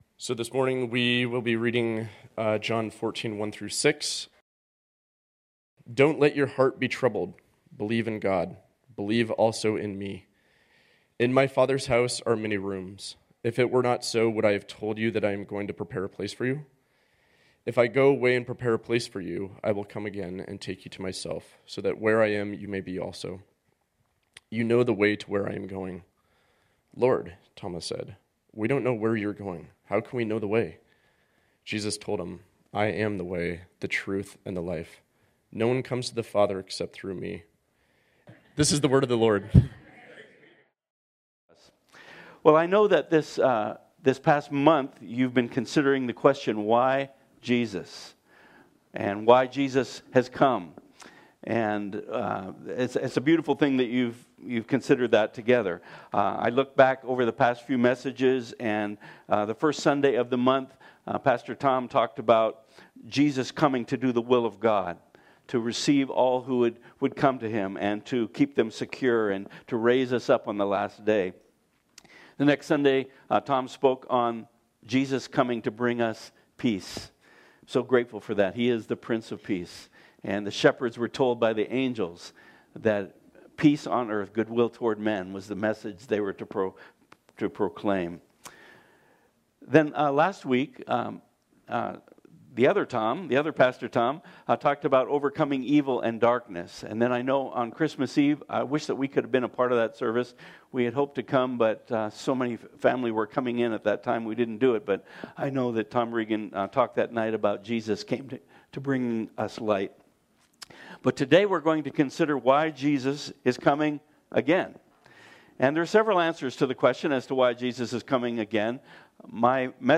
This sermon was originally preached on Sunday, December 29, 2024.